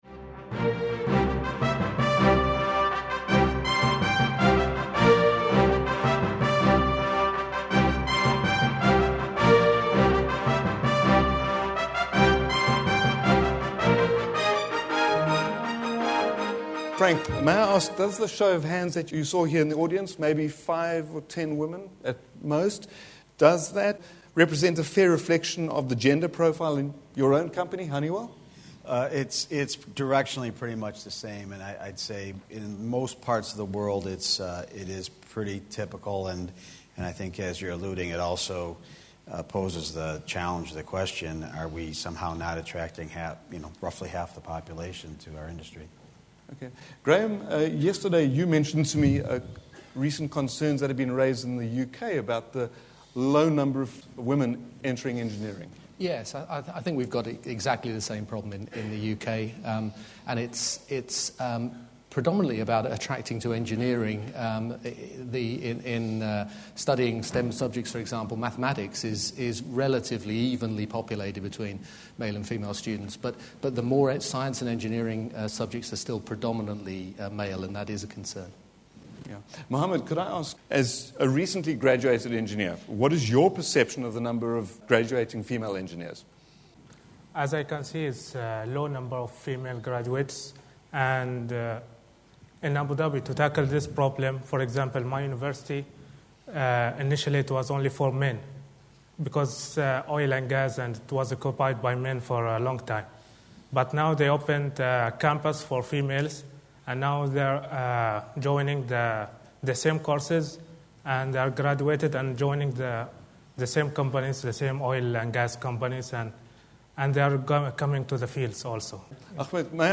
Honeywell Process Solutions recently hosted a panel debate on the shortage of engineering skills. Held during its 2011 Users’ Group Conference (HUG) for Europe, Middle East and Africa (EMEA), the panellists and audience debated numerous causes and solutions for the problem, including: gender disparity, age, government intervention, corporate training programmes, quality of education, and motivation of young people into the field of engineering.